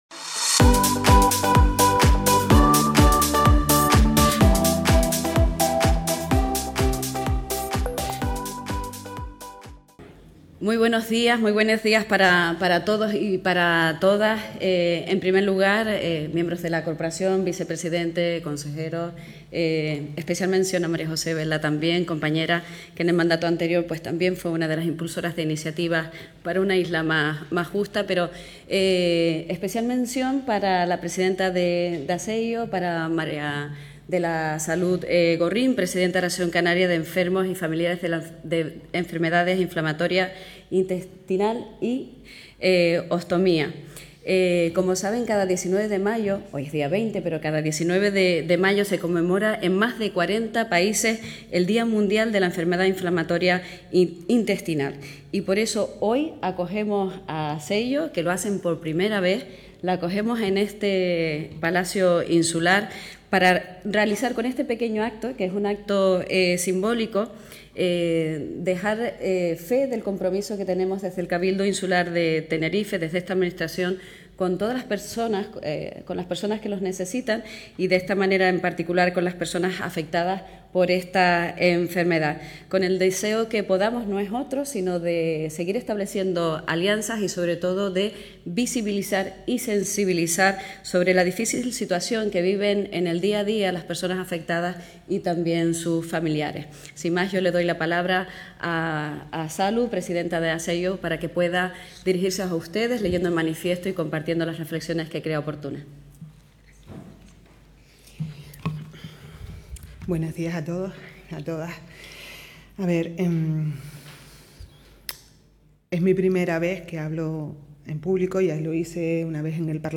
El Salón Noble del Cabildo de Tenerife acogió hoy (lunes 20) la lectura de un manifiesto de apoyo a los afectados por la Enfermedad Inflamatoria Intestinal y Ostomía y a sus familiares.